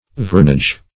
Search Result for " vernage" : The Collaborative International Dictionary of English v.0.48: Vernage \Ver"nage\ (v[~e]r"n[asl]j), n. [It. vernaccia.] A kind of sweet wine from Italy.